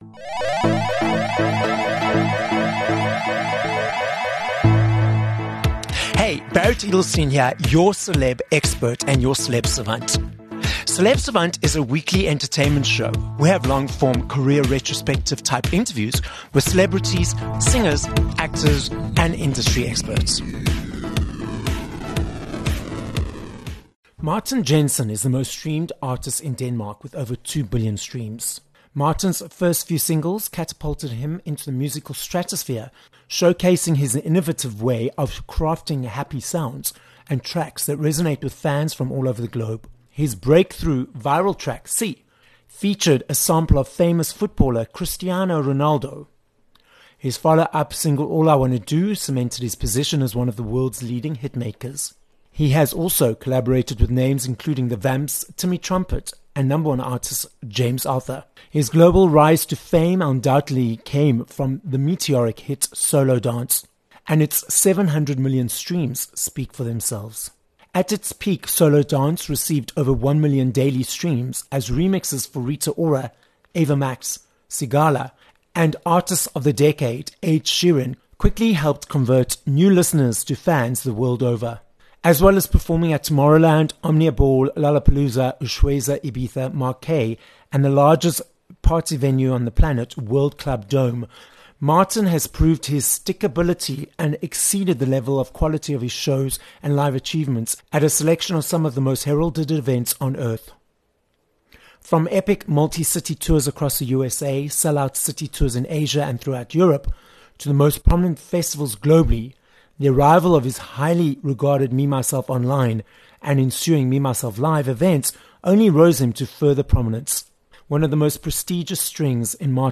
23 Nov Interview with Martin Jensen